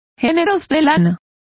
Complete with pronunciation of the translation of woolens.